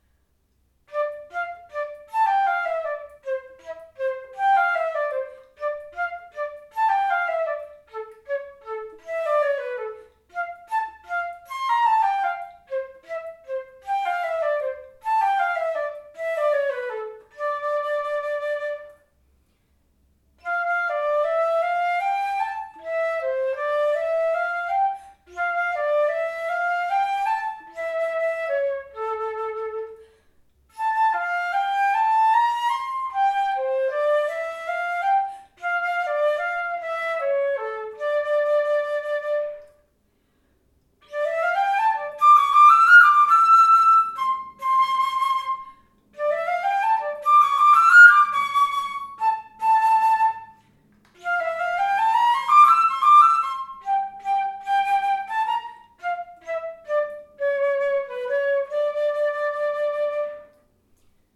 The Powell flute is a silver instrument with open-hole keys and a C foot.
As such, all the below recordings were made using the same recorder settings and player positioning (including microphone distance) within the same room.
Active:  Powell flute (1928)